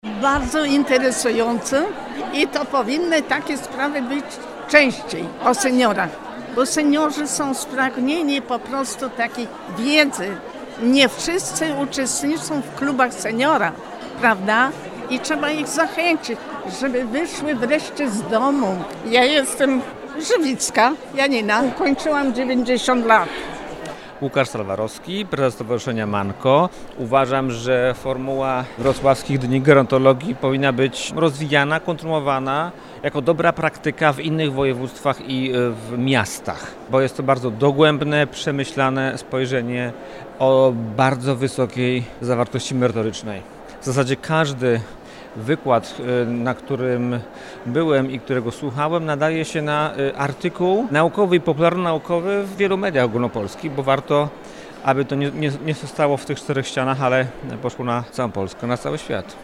O wrażenia pytamy w sondzie.
sonda-seniorzy.mp3